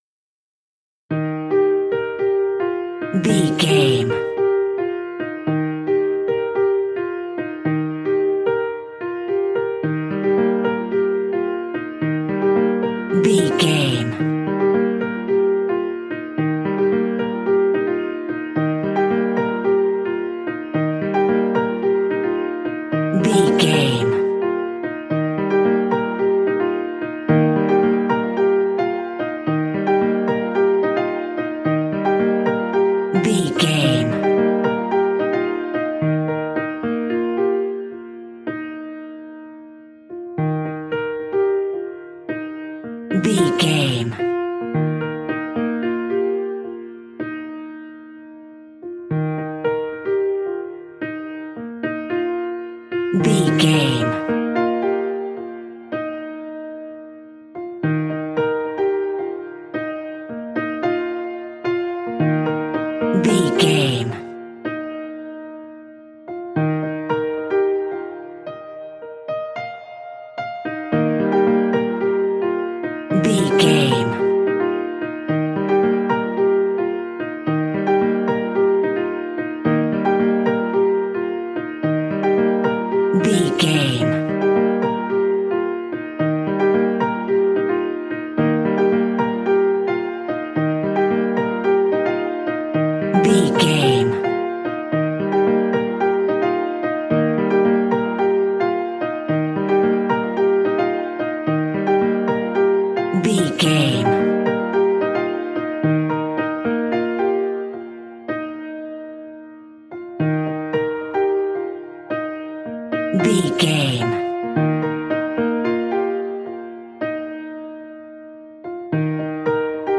Uplifting
Ionian/Major
E♭
kids instrumentals
fun
childlike
cute
happy
kids piano